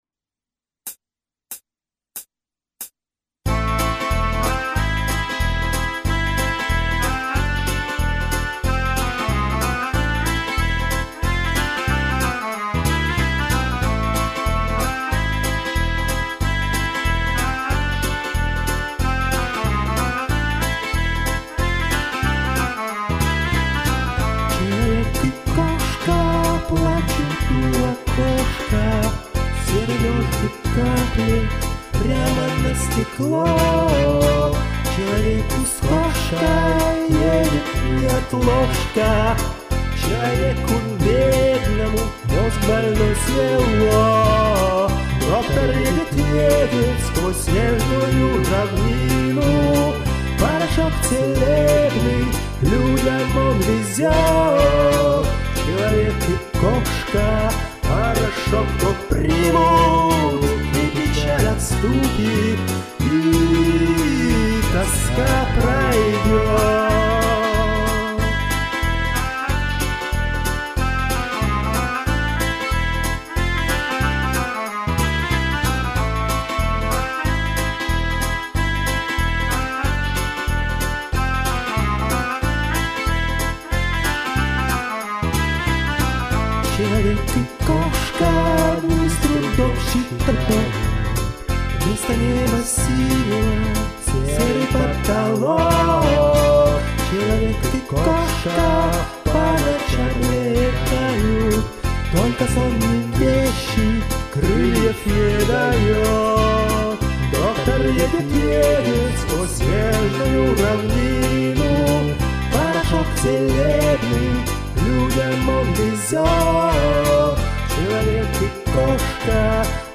Наличие 2-х голосов у обоих мне понравилось...